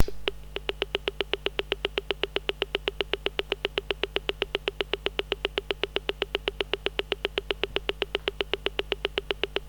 No beep. Just a cycling faint click-click-click sound coming from the speaker, which is synchronized with a thin horizontal line moving up and down over the screen (this line can't be seen from the image, because it is too thin).
Update: I made a recording of the sound from the speaker (attached)
The first click that you hear is the PSU On switch.
Then there's a first alone speaker click followed by a train of closer clicks (frequency of the clicks seems to be slightly below 8 Hz)
clicking_7-8hz.ogg